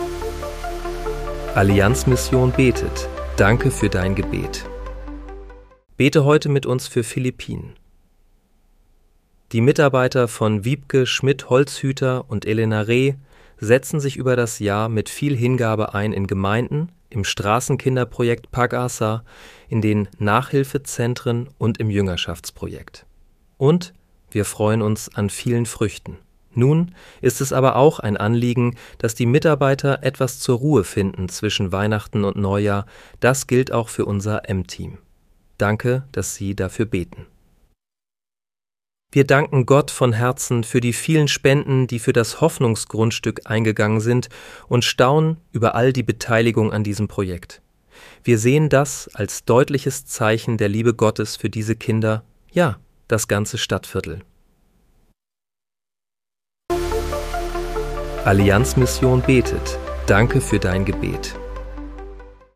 Bete am 29. Dezember 2025 mit uns für Philippinen. (KI-generiert